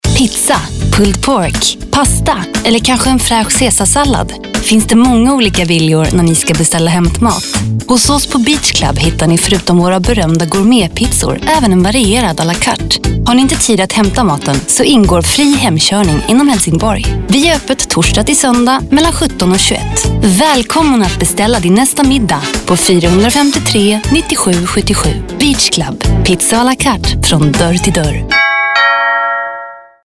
Commercial 1